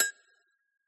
agogoLow.mp3